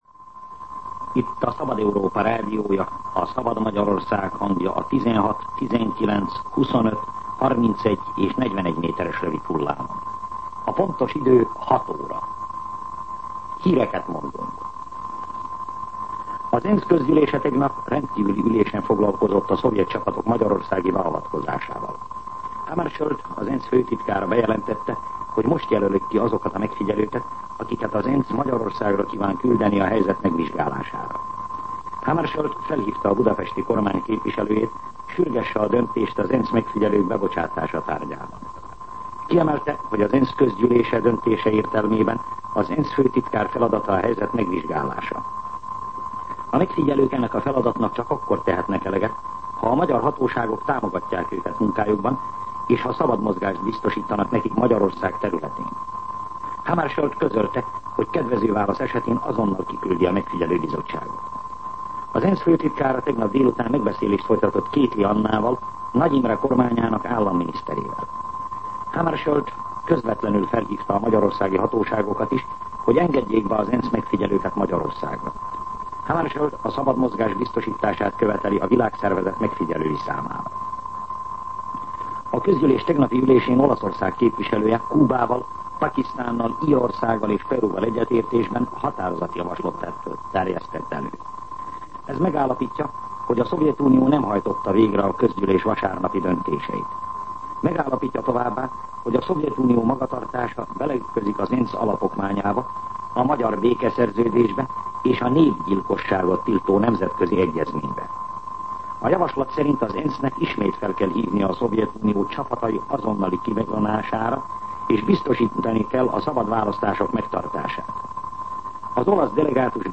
06:00 óra. Hírszolgálat